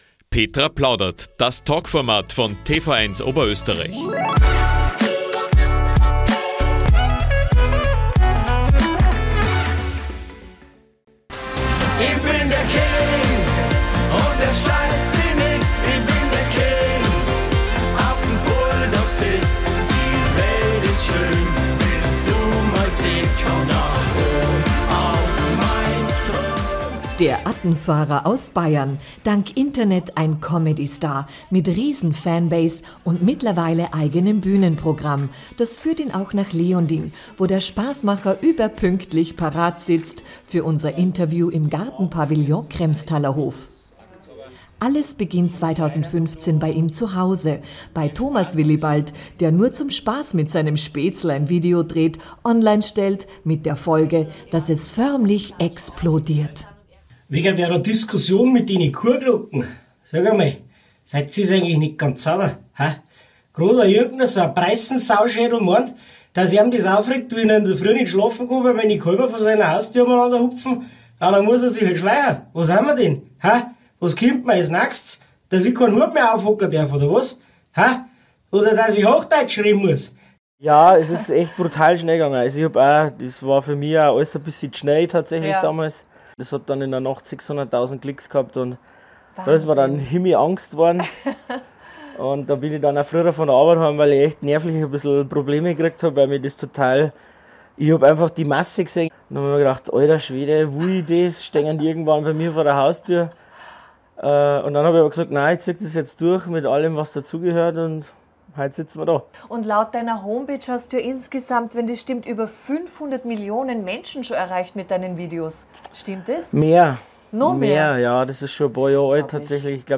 in Leonding (Kremstalerhof)